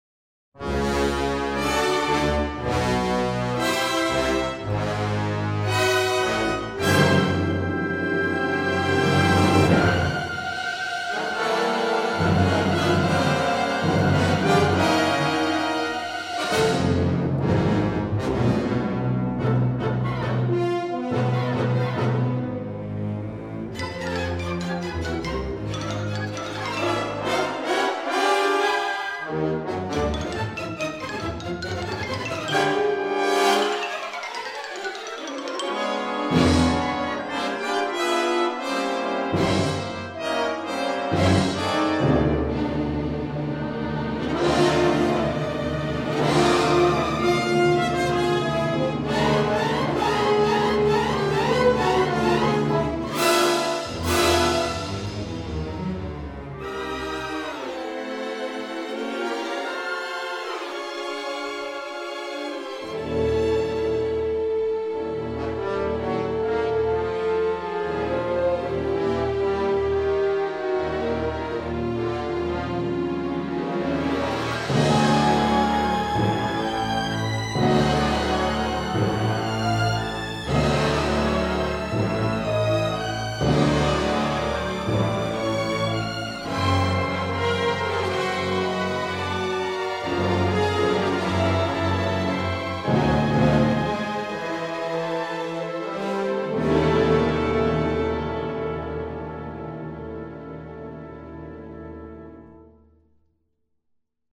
he took a serious and menacing approach to the music,